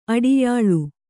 ♪ aḍiyāḷu